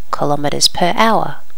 Normalize all wav files to the same volume level.